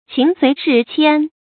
注音：ㄑㄧㄥˊ ㄙㄨㄟˊ ㄕㄧˋ ㄑㄧㄢ
情隨事遷的讀法